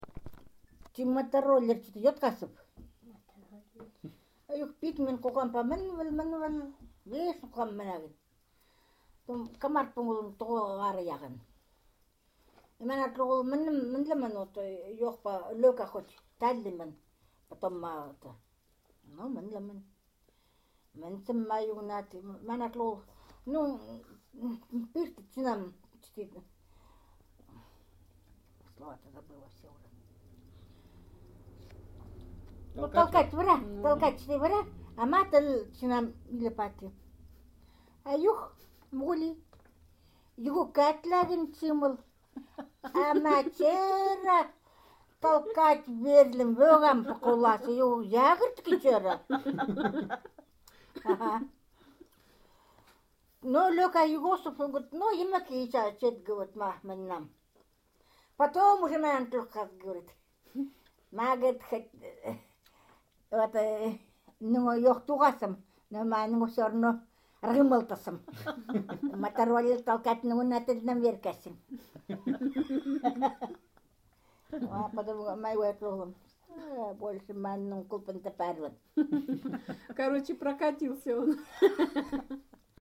These Eastern Khanty texts were recorded in 2007 in the upper and lower Vasyugan river areas, and in the Alexandrovo Ob’ river communities. The texts were narrated by the male and female Vasyugan Khanty and Alexandrovo Khanty speakers to other Khanty speakers and to the researchers, who also spoke limited Khanty and offered occasional interjections to the narration.